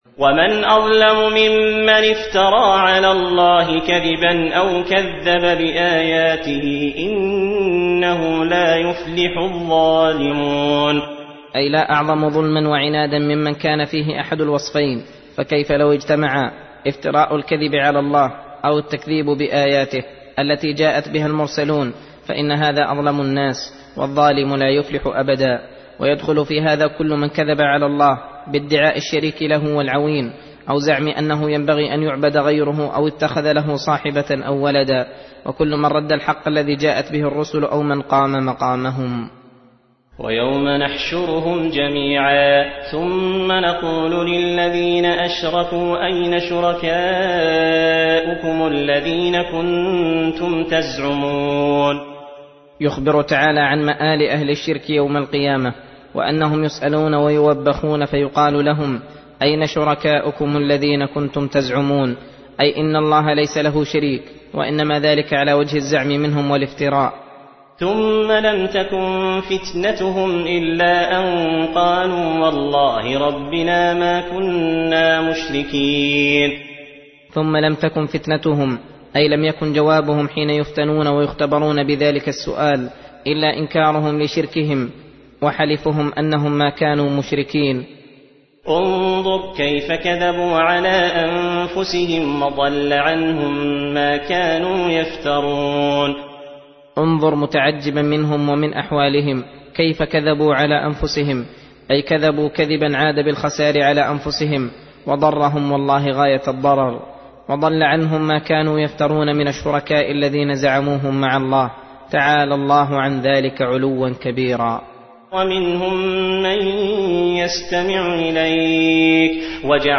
درس (26) : تفسير سورة الأنعام : (21-41)